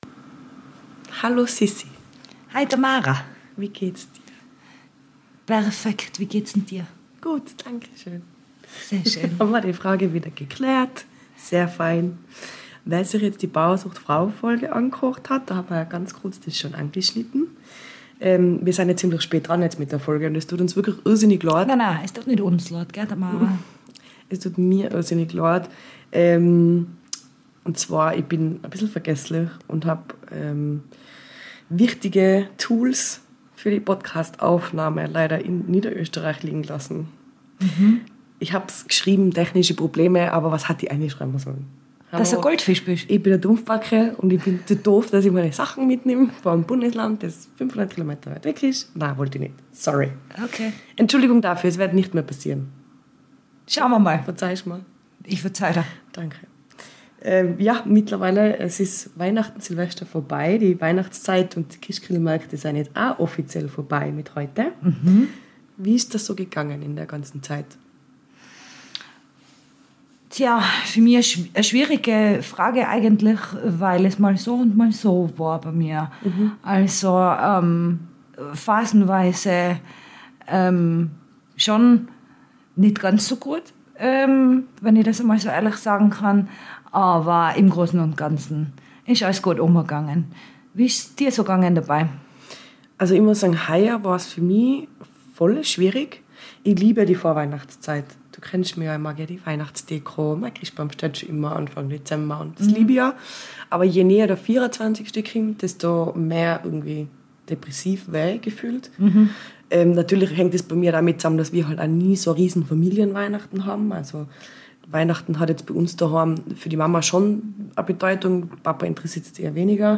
unsere folge zwoa entwickelte sich zu einem emotionalen und sehr privaten Gespräch über die Hürden des Alleinseins, Beziehungen, Trennungen und Herzschmerz, aber auch über die schönen Seiten der Unabhängigkeit als Single.